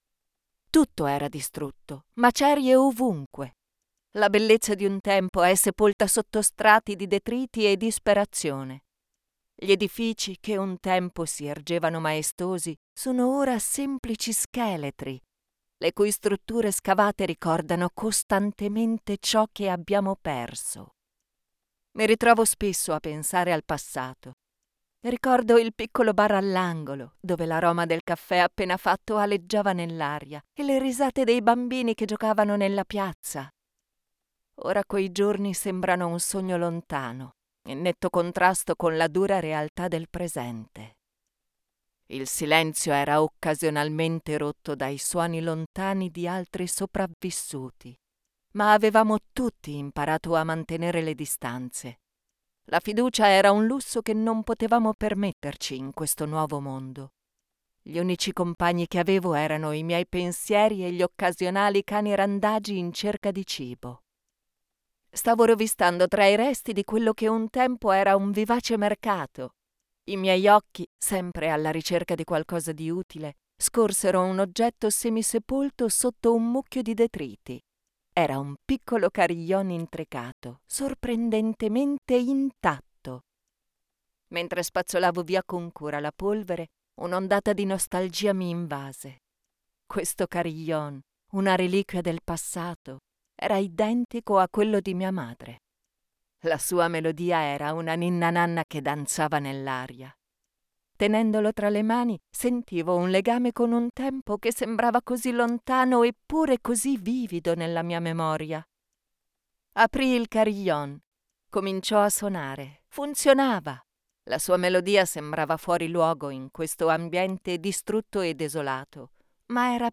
articulate, authentic, Deep, elegant, empathic, Formal, friendly
I record every day in my Home Studio: commercials, e-learning courses, tutorials, documentaries, audio guides, messages for telephone exchanges, audio for promo videos, narrations for radio broadcasts, audiobooks, audio for videogames, and much more.